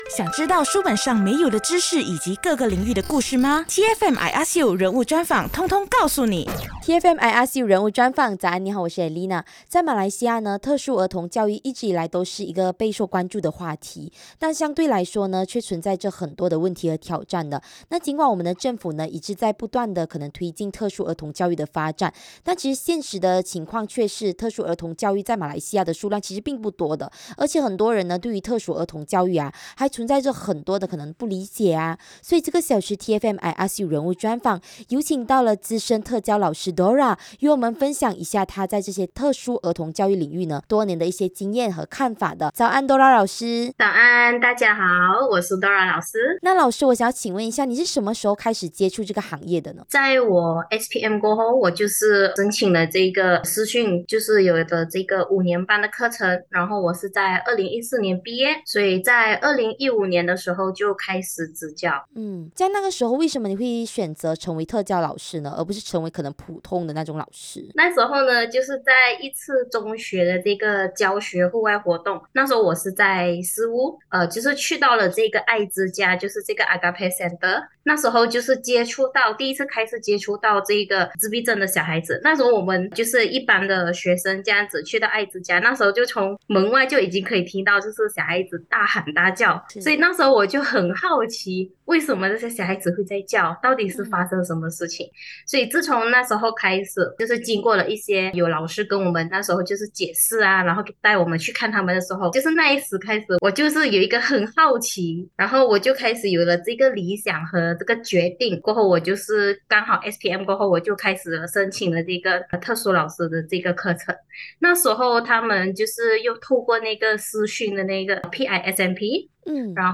人物专访 特教老师